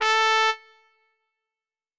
Cow_Life_Sim_RPG/Sounds/SFX/Instruments/Trumpets/doot6.wav at e69d4da15373a101a490e516c925cbcdf63458a3
doot6.wav